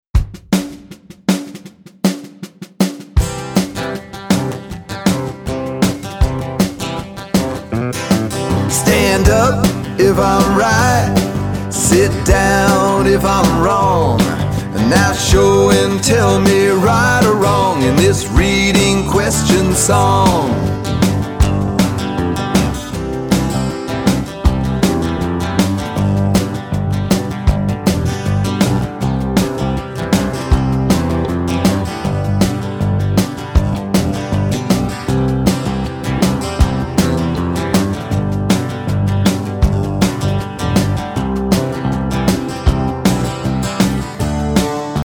Listen to the open version of this song.